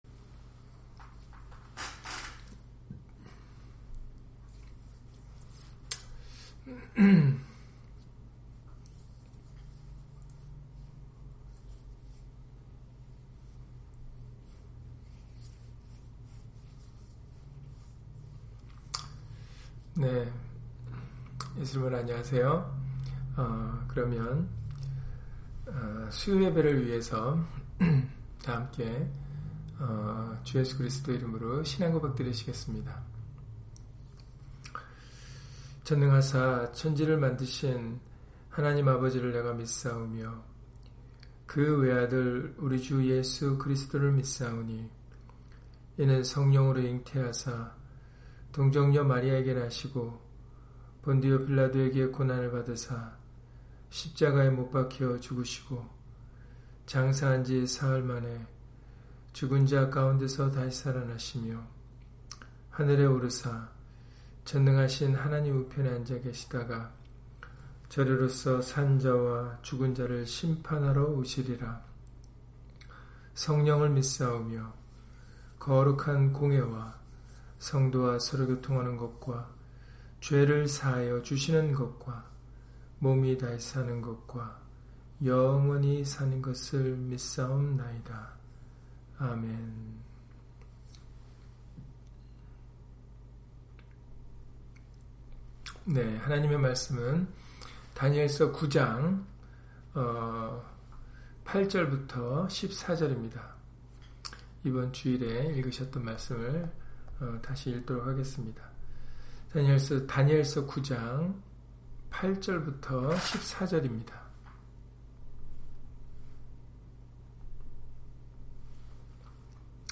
다니엘 9장 8-14절 [하나님께는 긍휼과 사유하심이 있사오니] - 주일/수요예배 설교 - 주 예수 그리스도 이름 예배당